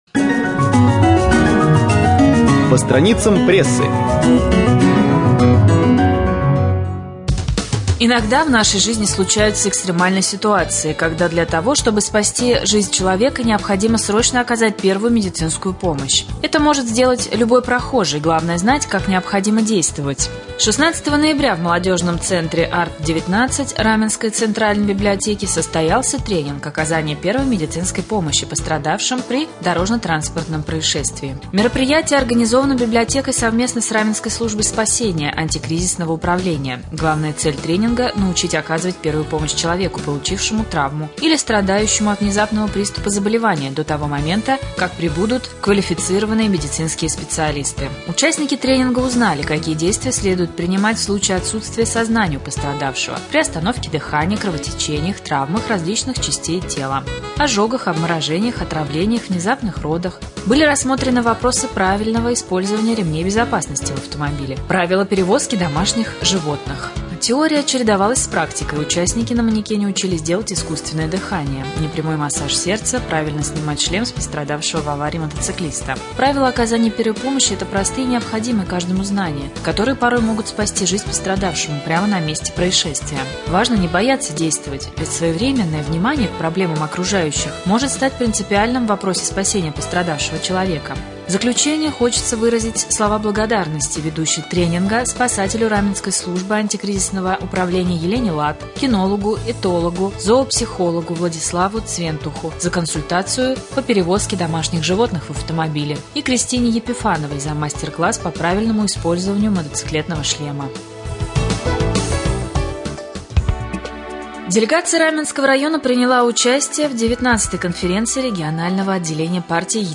1.Рубрика «По страницам прессы». Новости